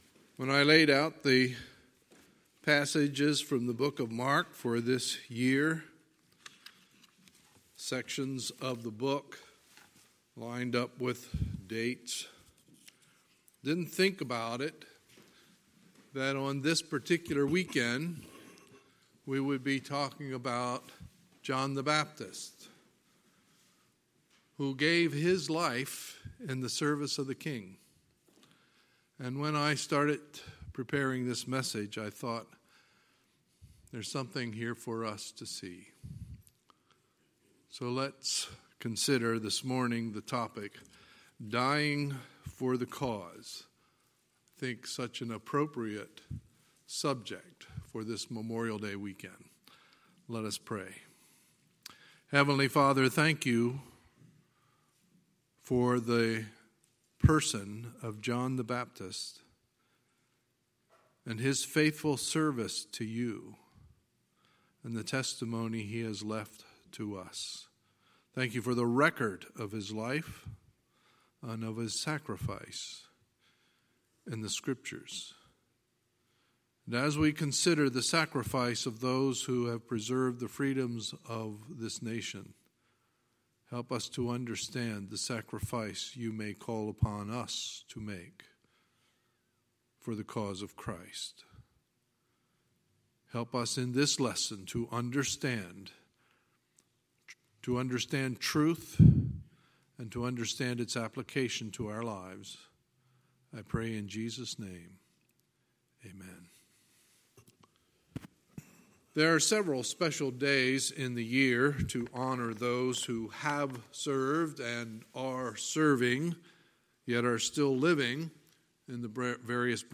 Sunday, May 26, 2019 – Sunday Morning Service